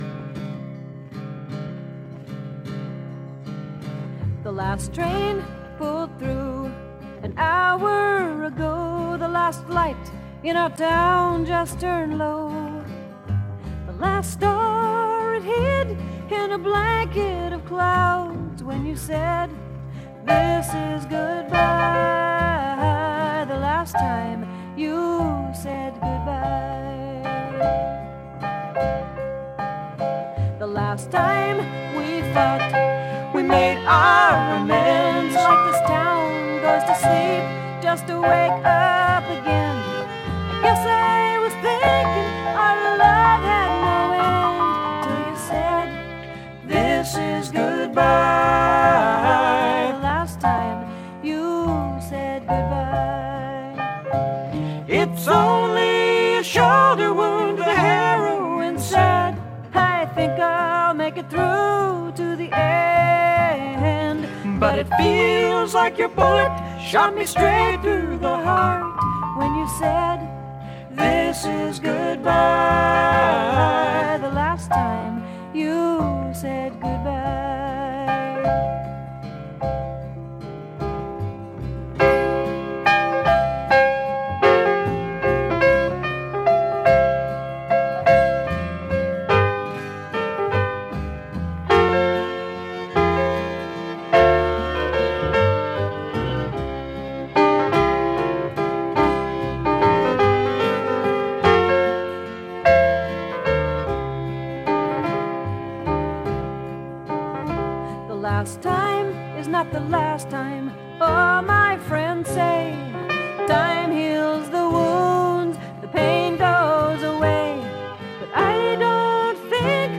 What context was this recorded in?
3 song vinyl EP